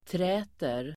Uttal: [tr'ä:ter]